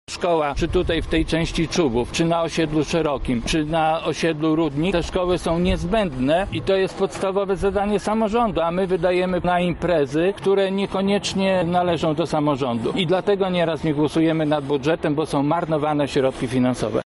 Berylowa 2 – mówi Zdzisław Drozd, radny klubu Prawo i Sprawiedliwość.